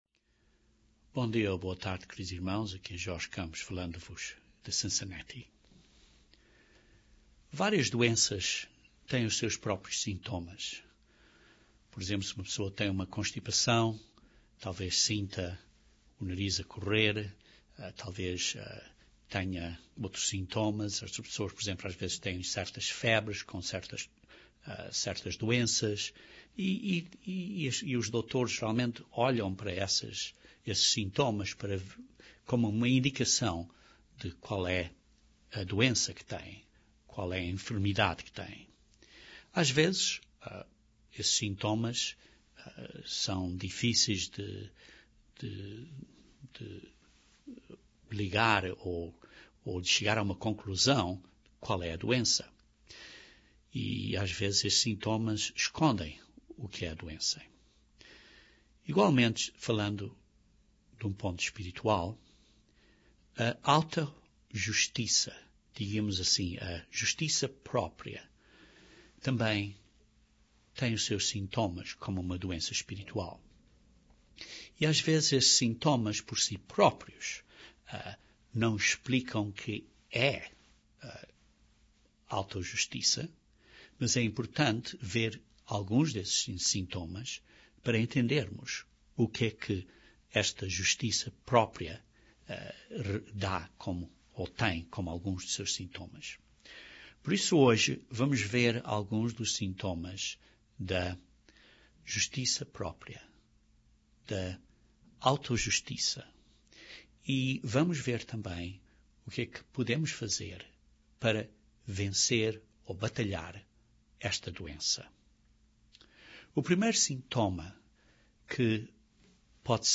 O que é a diferença entre a auto-justiça e a justiça de Deus? Este sermão cobre este tema.